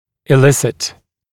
[ɪ’lɪsɪt][и’лисит]вызывать, извлекать